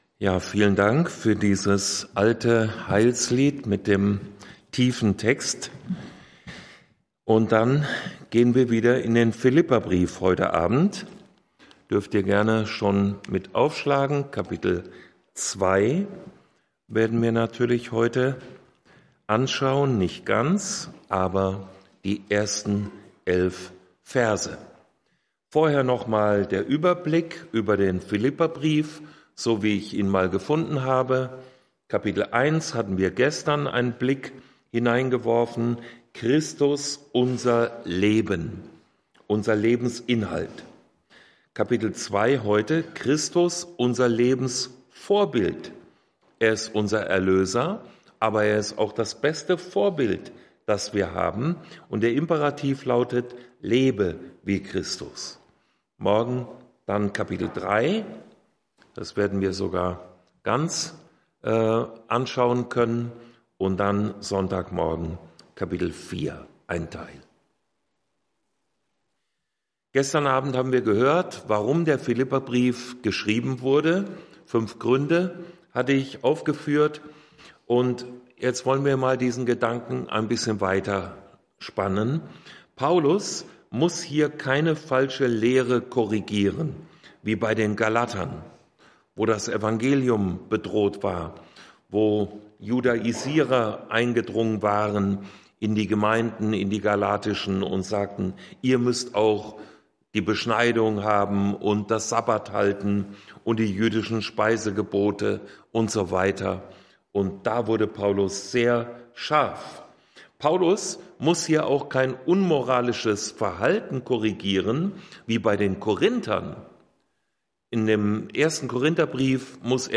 Vortragsreihe